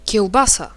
Ääntäminen
Ääntäminen : IPA: [kɔrv] Haettu sana löytyi näillä lähdekielillä: ruotsi Käännös Ääninäyte Substantiivit 1. kiełbasa {f} Artikkeli: en .